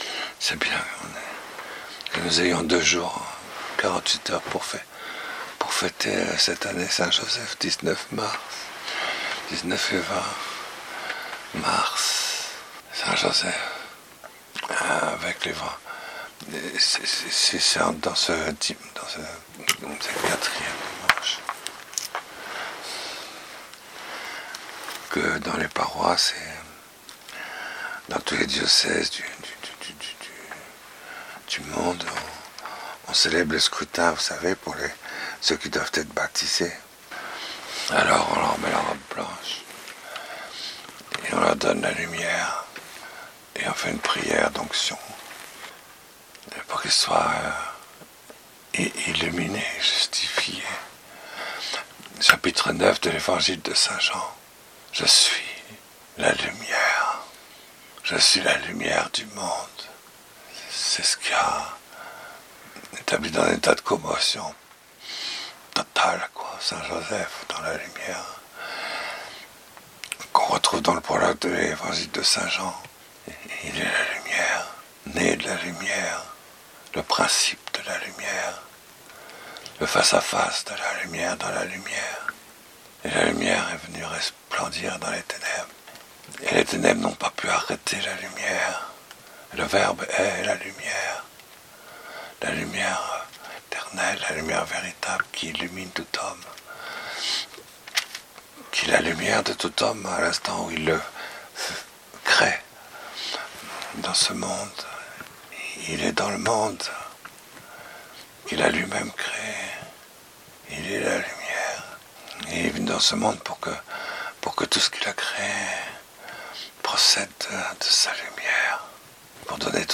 Hom�lie de la Messe de l'Aurore, 5�me Dimanche du Car�me, 26 mars Ez�chiel 37, 12-14 ; Psaume 129 ; Romains 8, 8-11 ; Jean 11, 1-45 Je suis la R�surrection... et la Vie ...
19-1Homelie.mp3